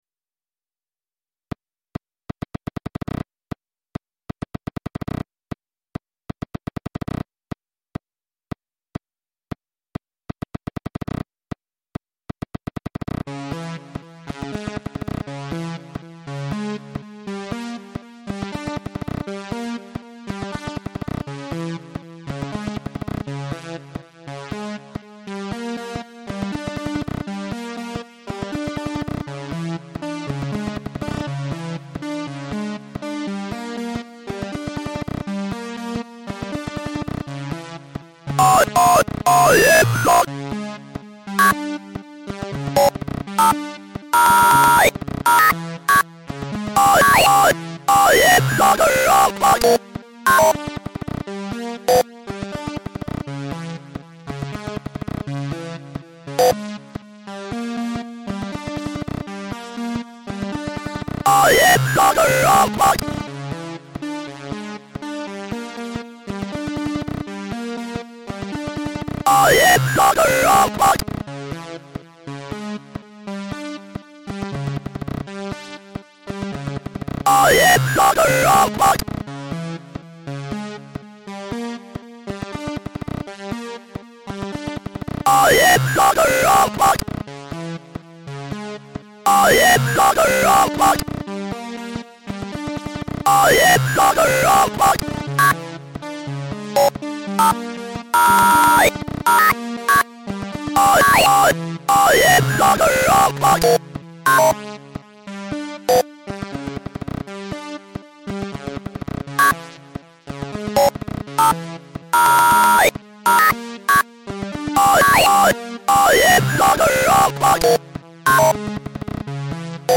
Hier gibt es meine Experimente mit Tönen und Geräuschen sowie diverser Hard- und Software zur Klangerzeugung.